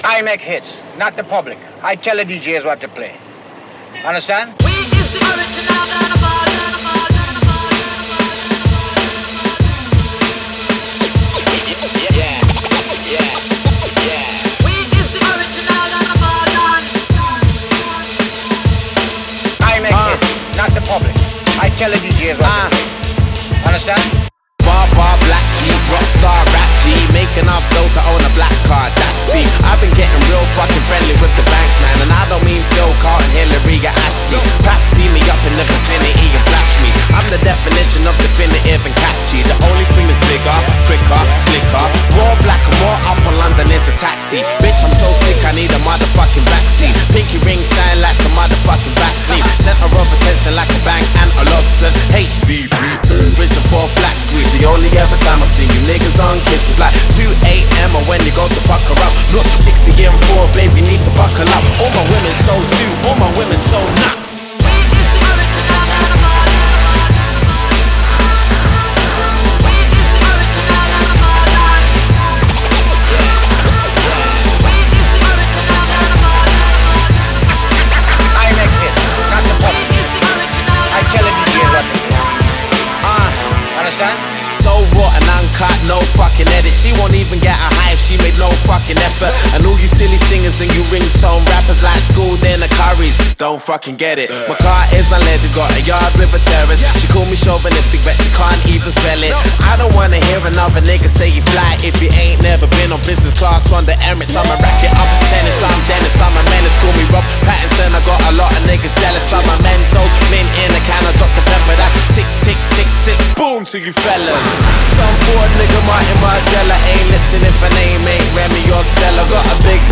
Жанр: R&B, club, HIP - HOP